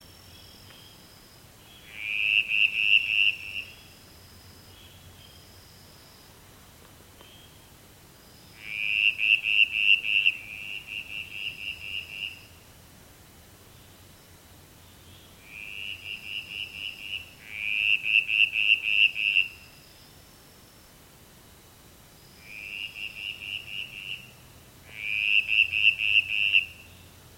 Six species of frog were calling from dams in Flowerdale in mid-February. These recordings were made on properties in Spring Valley Rd and Old Spring Valley Rd.
Southern Brown Tree Frog (Litoria paraewingi)
s-brown-tree-frog.mp3